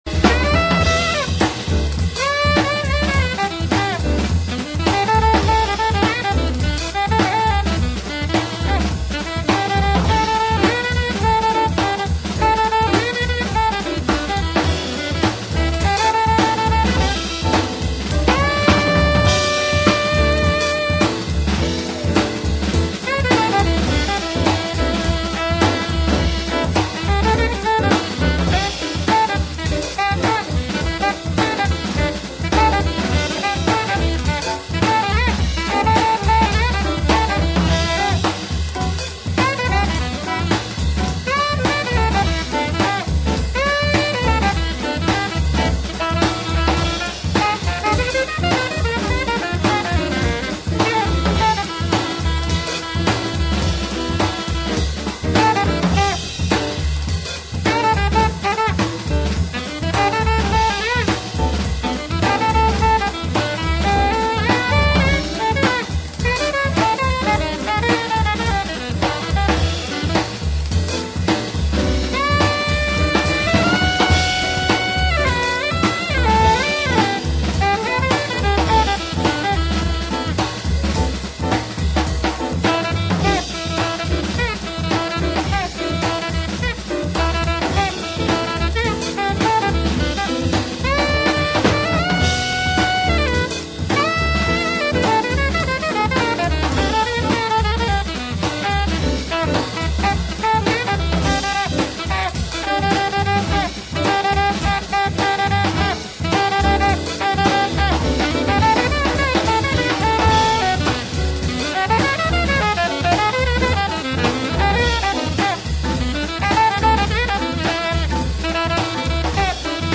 альт-саксофон
в московском "Ле-Клубе"( 3 мин. 30 сек.)